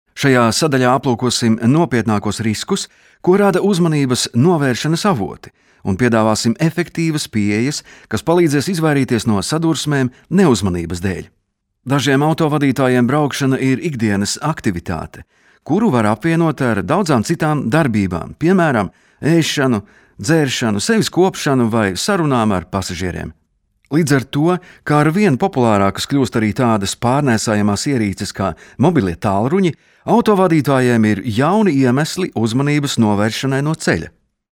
Erkek Ses